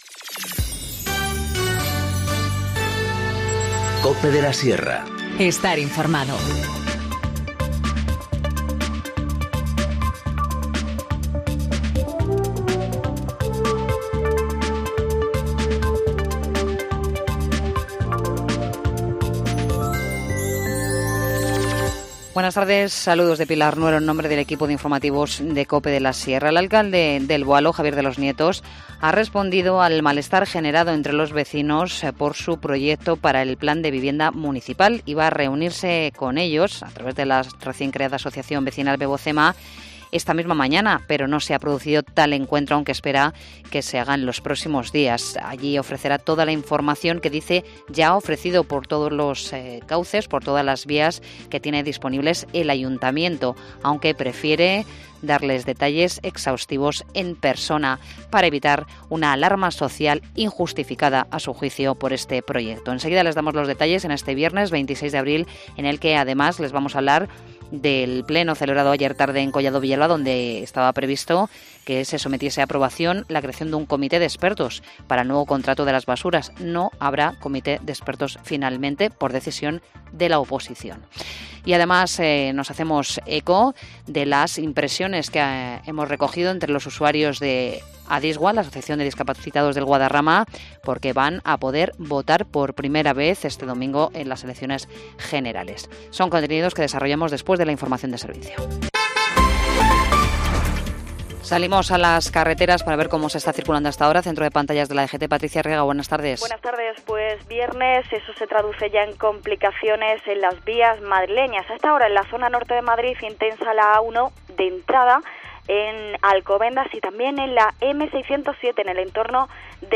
Informativo Mediodía 26 abril 14:20h